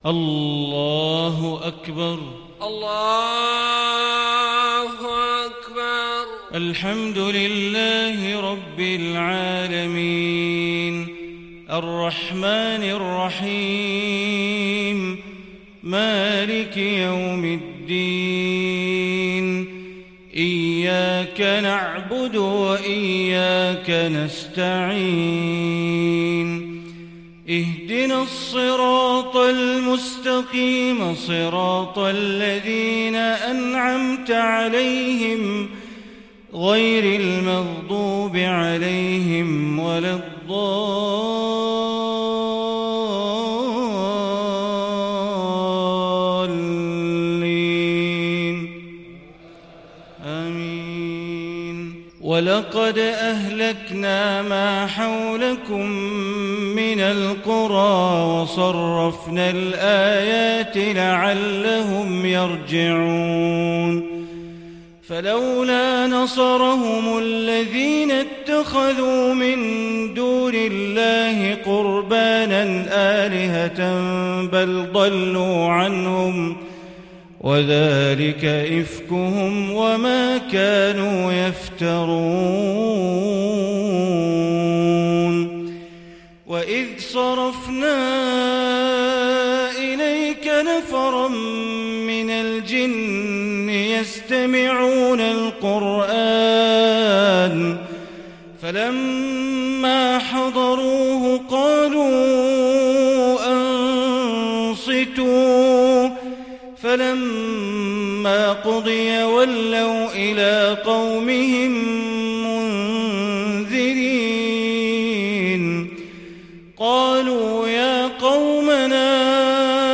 صلاة العشاء للشيخ بندر بليلة 6 جمادي الآخر 1441 هـ
تِلَاوَات الْحَرَمَيْن .